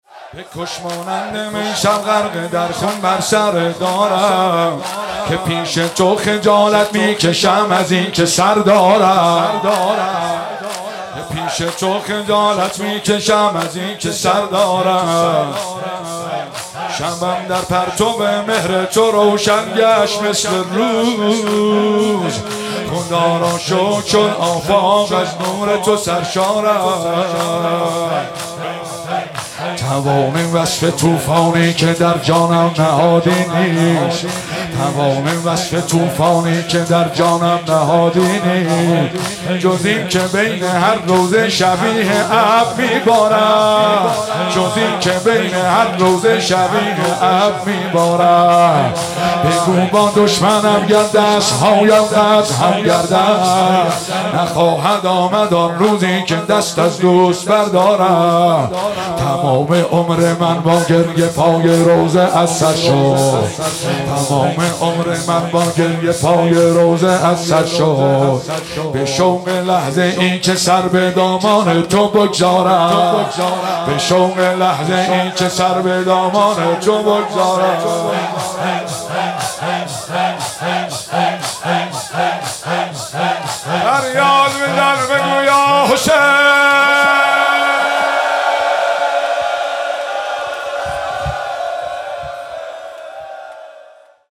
تک جدید